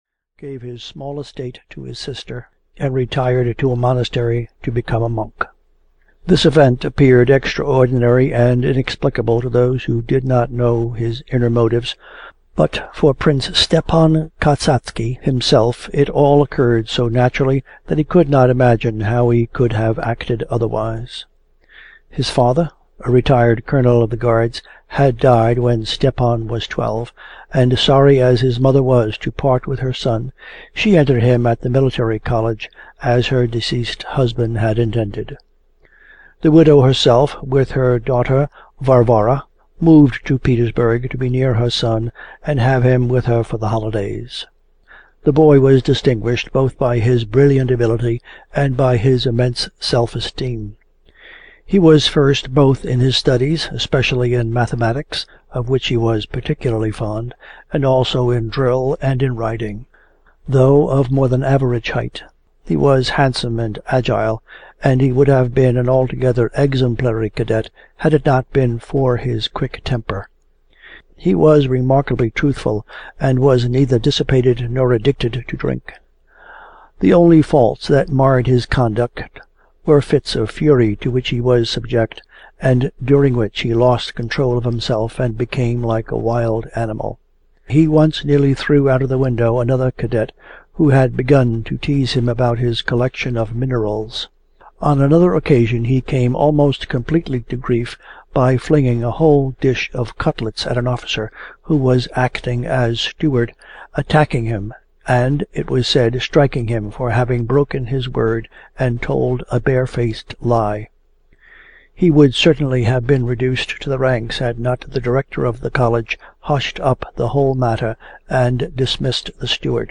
Father Sergius (EN) audiokniha
Ukázka z knihy